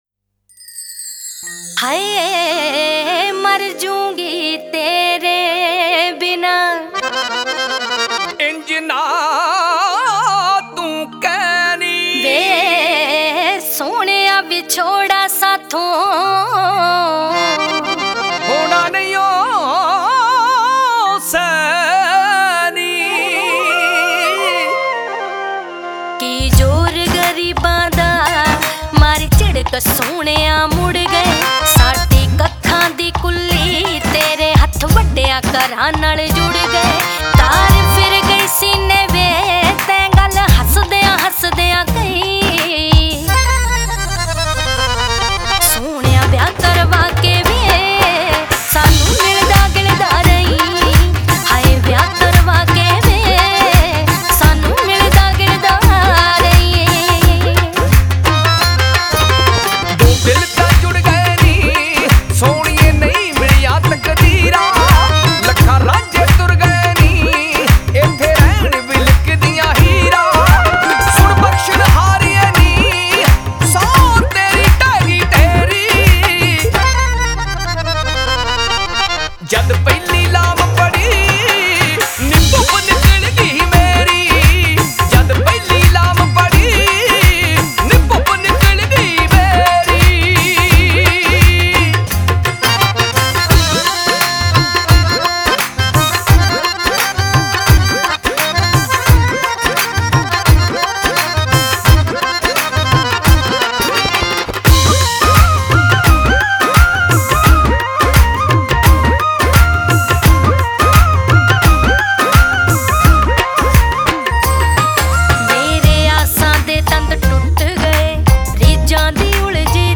Song Genre : Punjabi Old Song 2